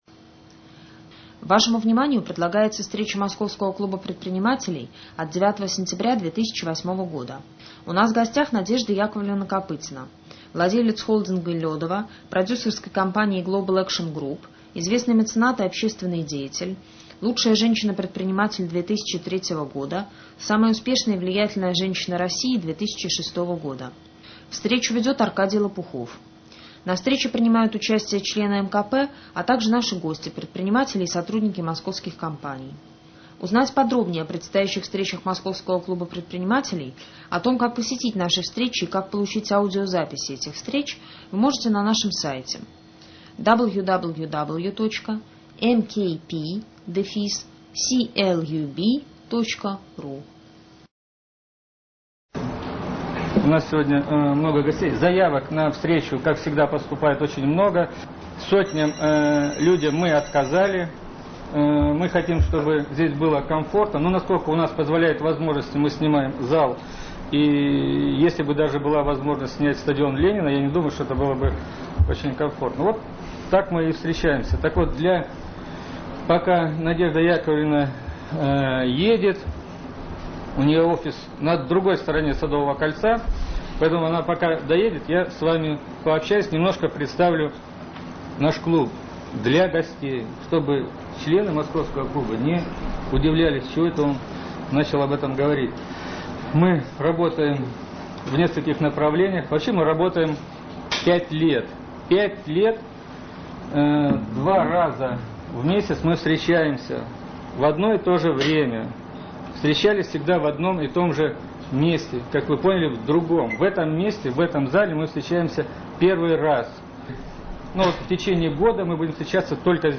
Мероприятие ОТКРЫТАЯ встреча МКП